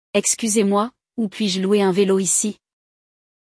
No episódio de hoje do Walk ‘n’ Talk Essentials Francês, vamos acompanhar um diálogo entre um homem e uma atendente, onde ele aprende a alugar um vélo (bicicleta) de maneira educada e eficiente.